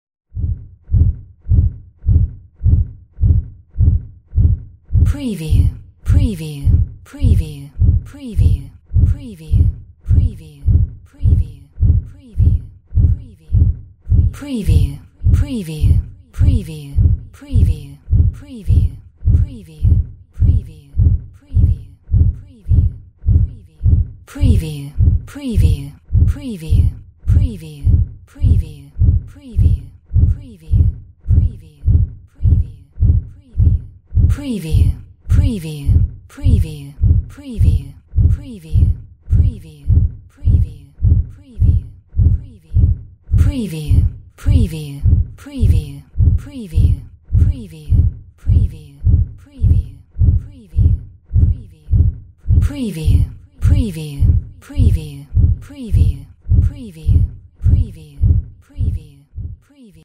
Ventilation fan
Stereo sound effect 16 bit/44.1 KHz and Mp3 128 Kbps
PREVIEW_ELEC_VENTILATION_FAN01.mp3